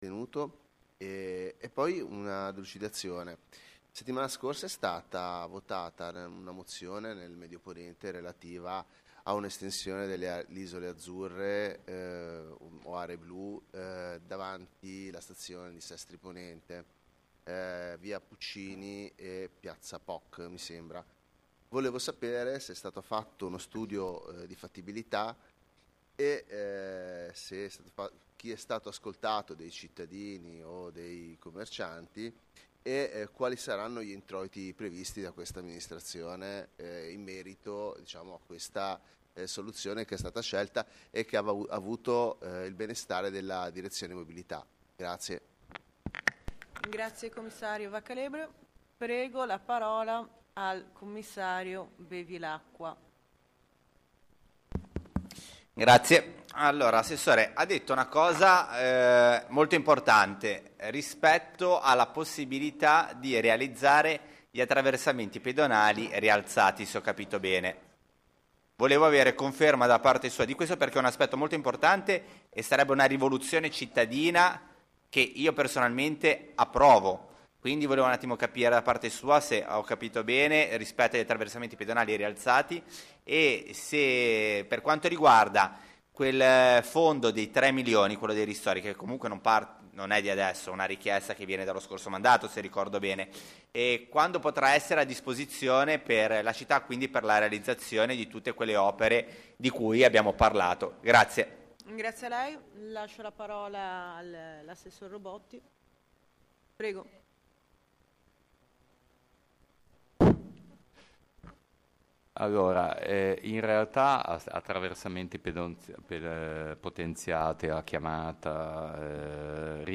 Sedute del Consiglio Comunale